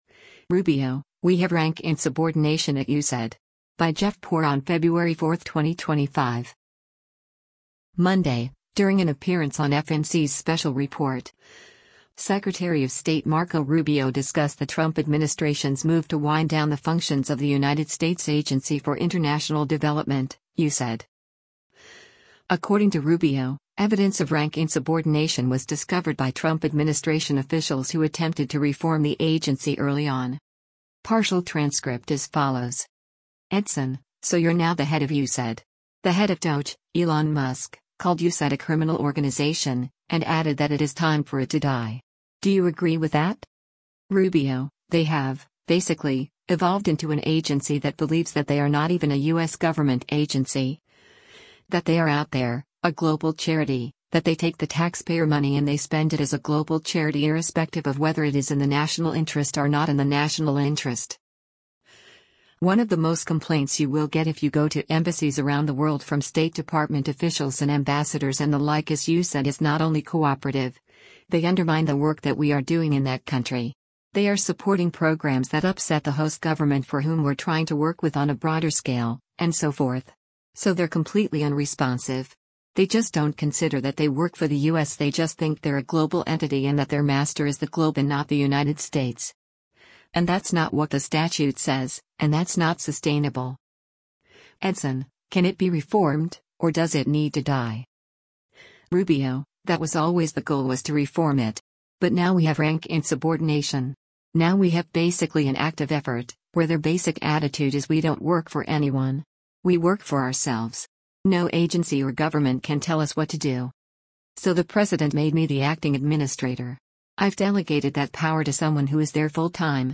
Monday, during an appearance on FNC’s “Special Report,” Secretary of State Marco Rubio discussed the Trump administration’s move to wind down the functions of the United States Agency for International Development (USAID).